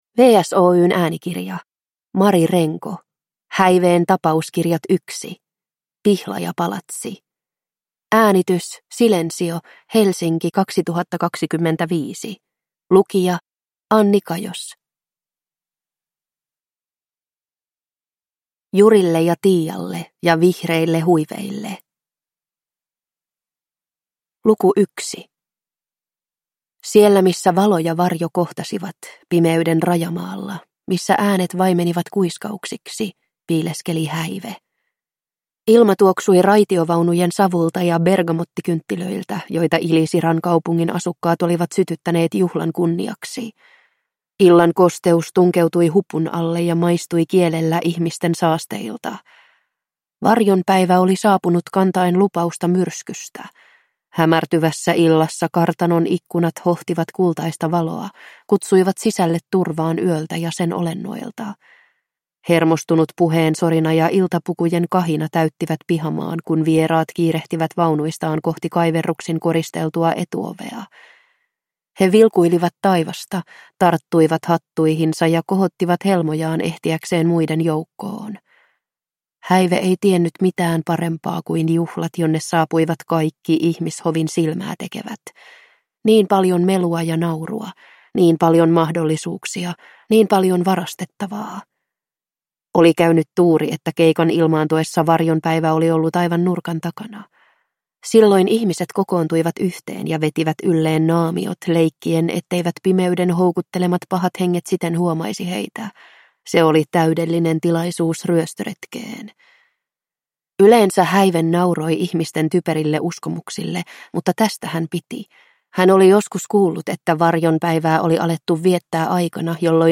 Häiveen tapauskirjat: Pihlajapalatsi – Ljudbok